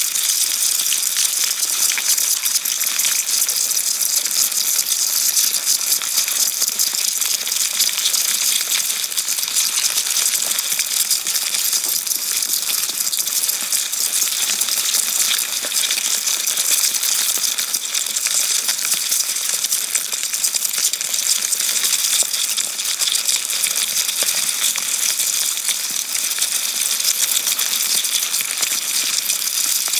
• field recording of dubia roaches.wav
Close range recording of Dubia Roaches with the DPA 4060 CORE Normal-Sensitivity Omni Lavalier Microphone x 2. Captured with the Sound Devices MixPre-10 II 24-bit/48khz.